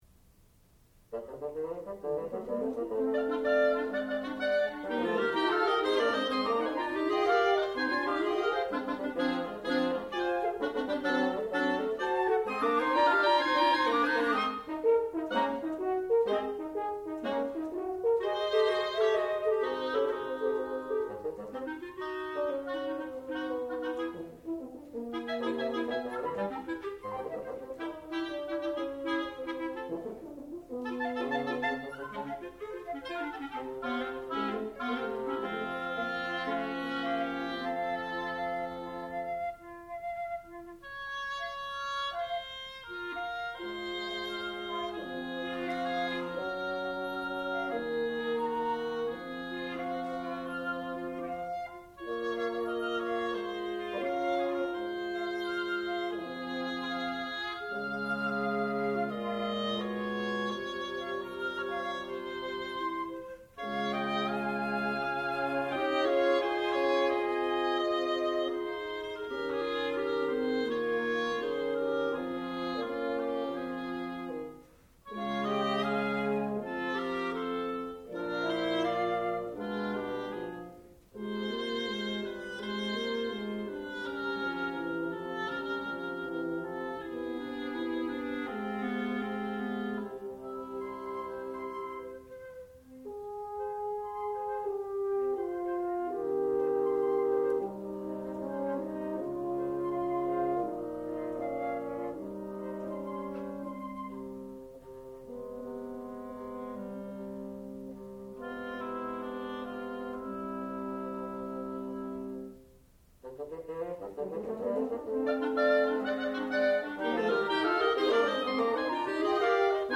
sound recording-musical
classical music
bassoon
horn
flute